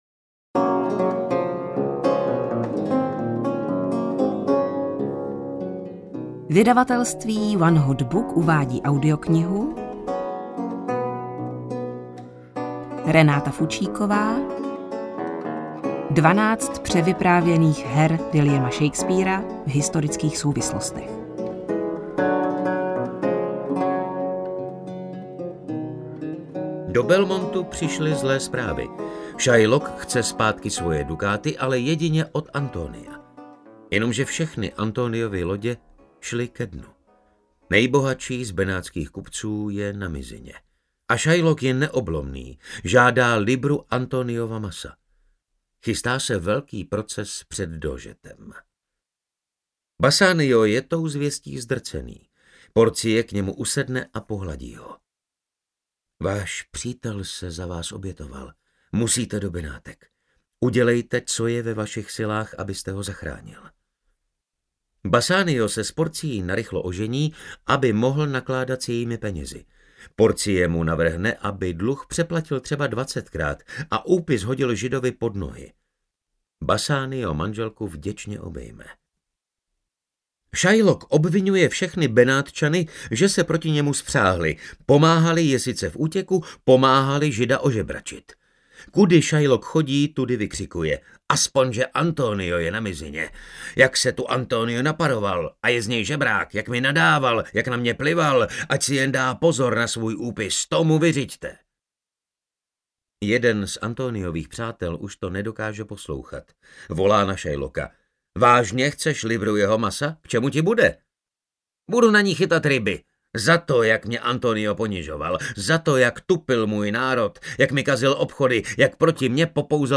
Shakespeare audiokniha
Ukázka z knihy